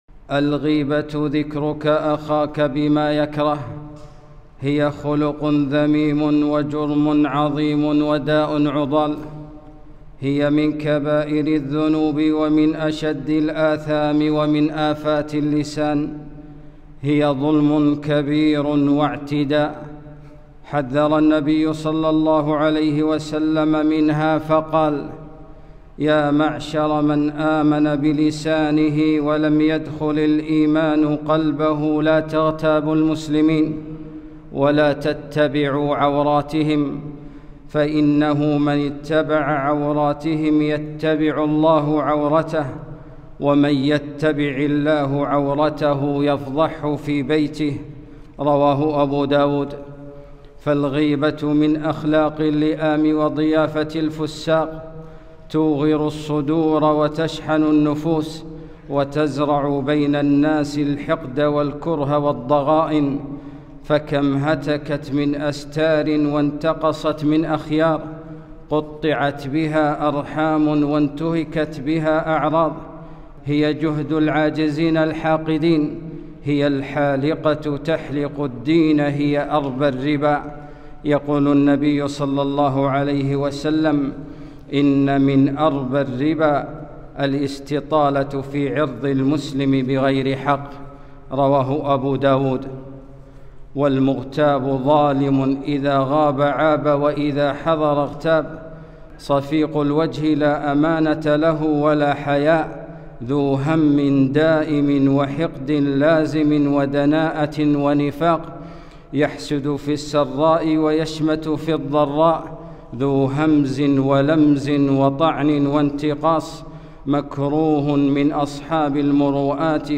خطبة - الغيبة